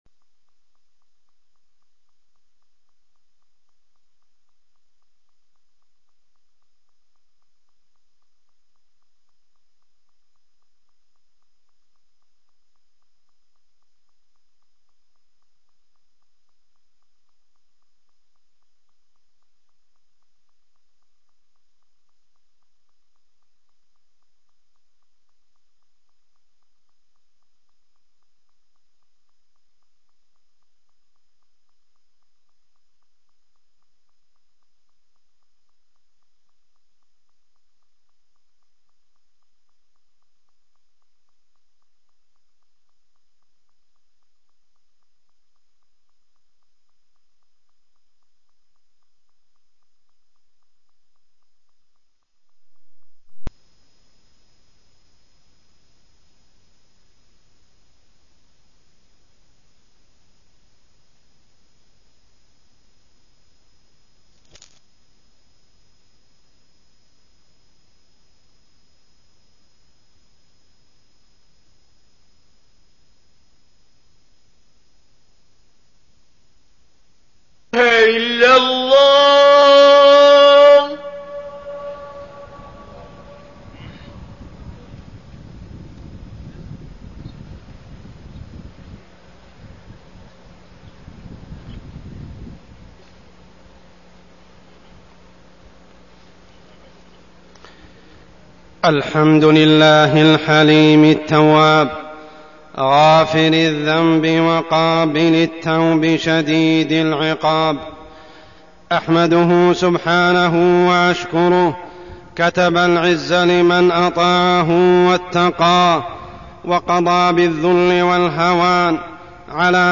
تاريخ النشر ٢٠ جمادى الأولى ١٤١٩ هـ المكان: المسجد الحرام الشيخ: عمر السبيل عمر السبيل المعاصي والذنوب